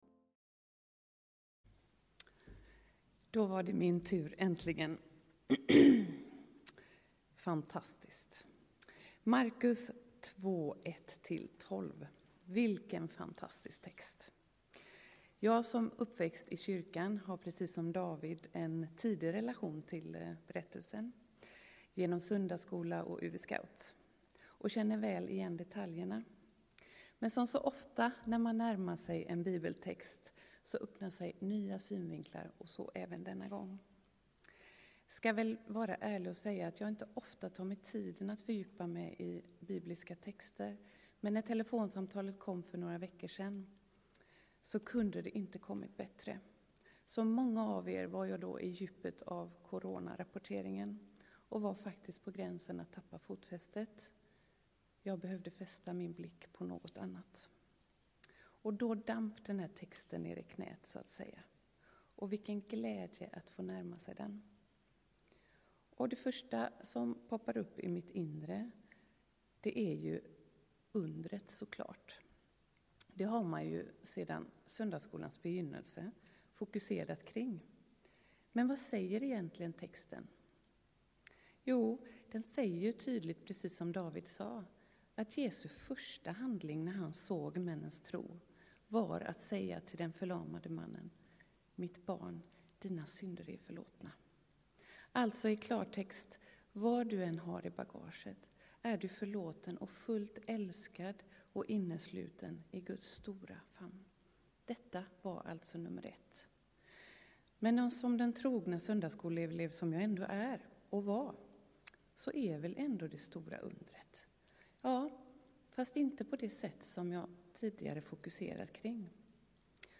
2×7 predikan